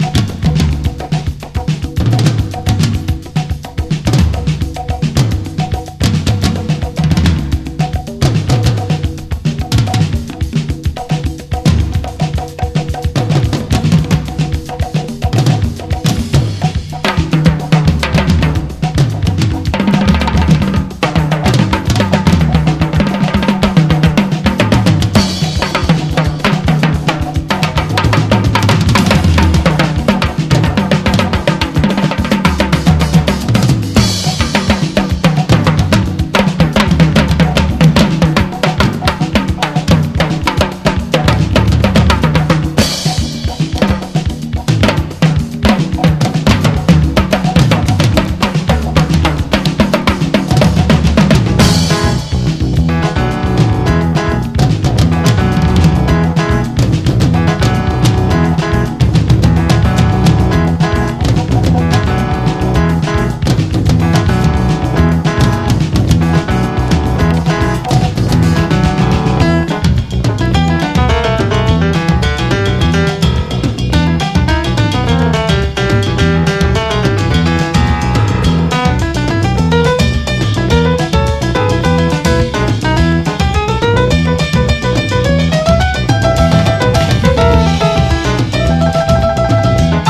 JAPANESE / 80'S / CITY POP / JAPANESE A.O.R.
和製アーバン・メロウ・ソウル/シティ・ポップ最高作！
ダンディズム溢れる歌い口、サヴァンナ・バンド的にノスタルジックな音使いもたまりません！